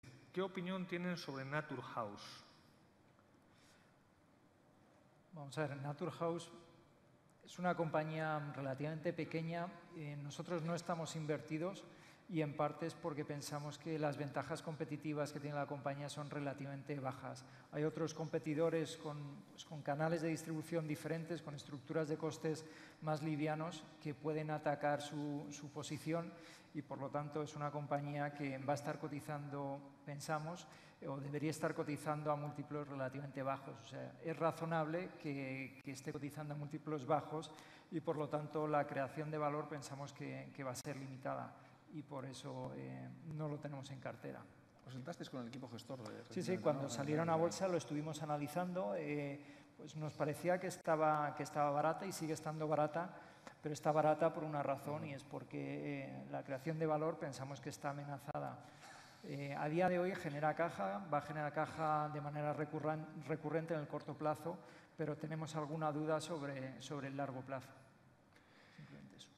Estos opinaban hace unos meses (en el turno de preguntas de su presentación a inversores) sobre la empresa NATURHOUSE del siguiente modo: